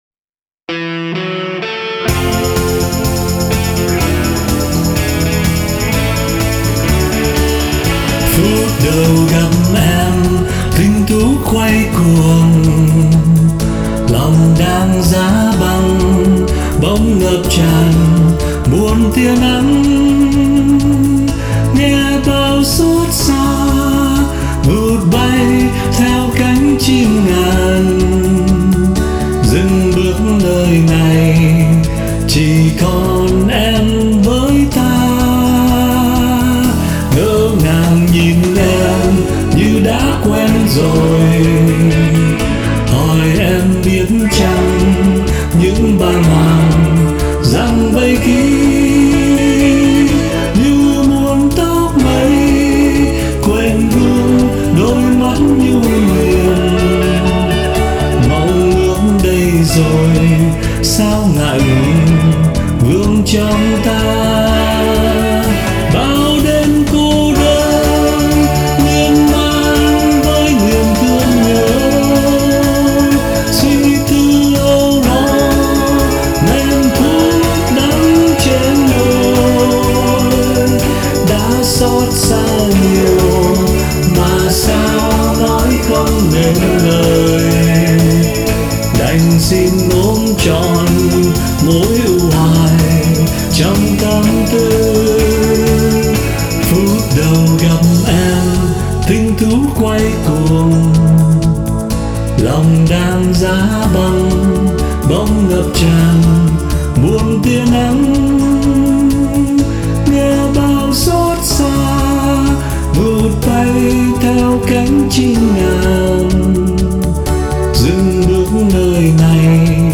Giọng hát đam mê trực tiếp đi vào lòng người nghe.